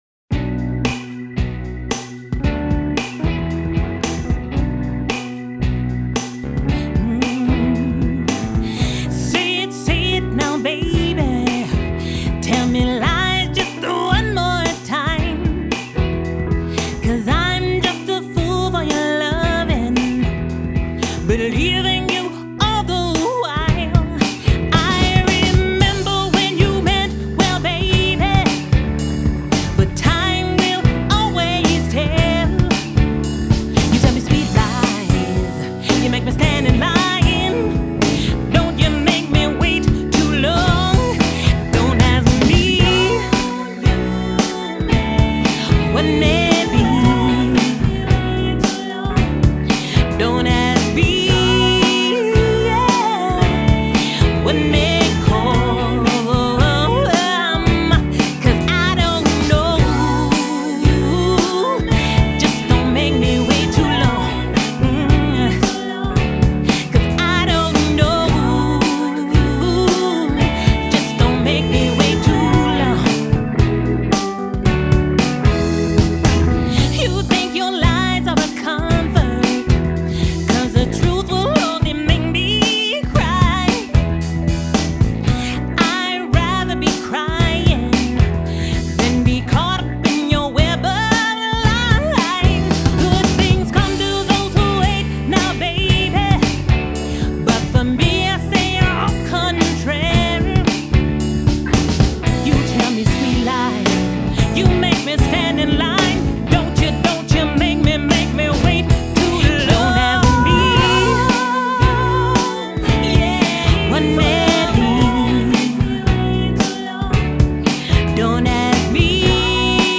Rock/Pop/Heavy
R&B Song